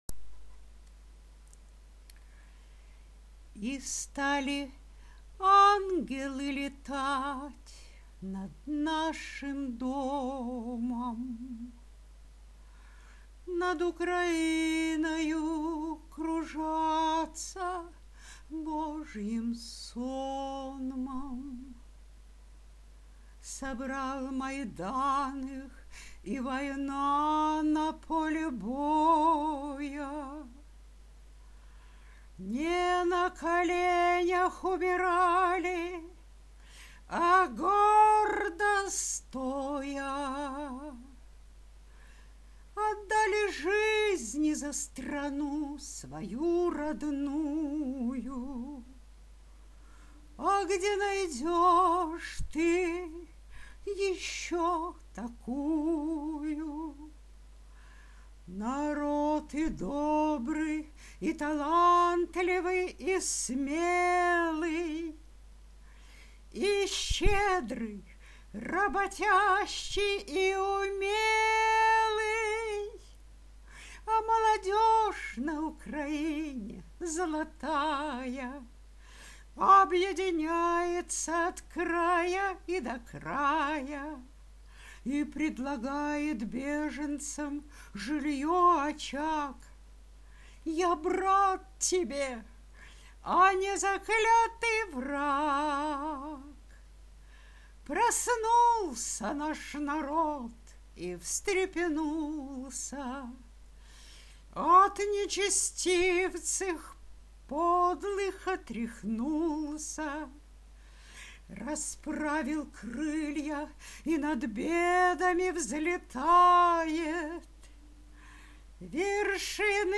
(Песня)
Рубрика: Поезія, Громадянська лірика